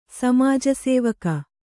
♪ samāja sēvaka